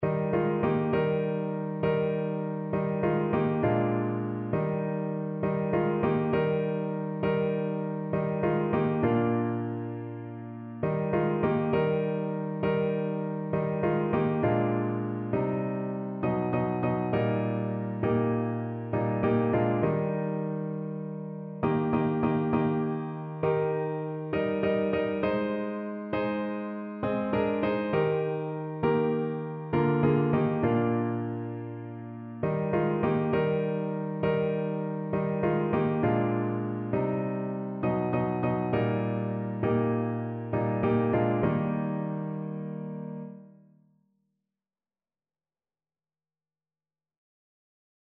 No parts available for this pieces as it is for solo piano.
9/8 (View more 9/8 Music)
Classical (View more Classical Piano Music)